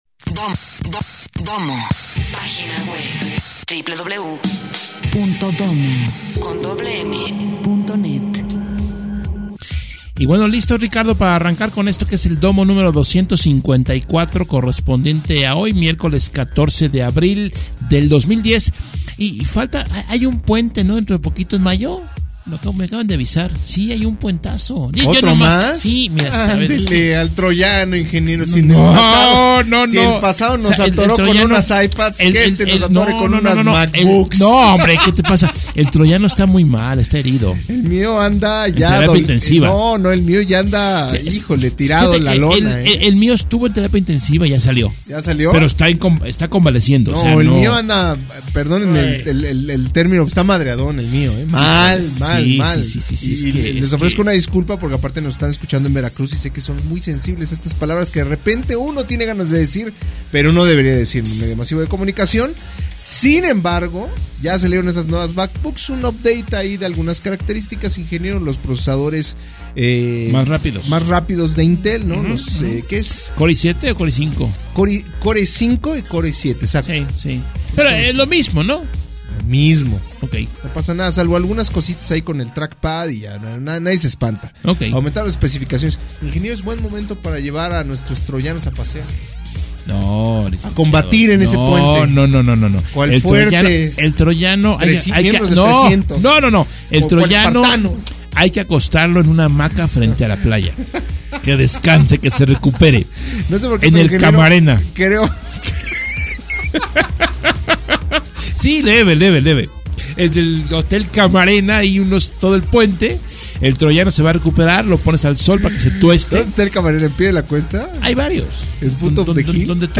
En esta emisión de Dommo se comenta el lanzamiento de antivirus para Mac por BitDefender , así como la posible venta de Palm ante las pérdidas que ha sufrido en el mercado de los smartphones . En entrevista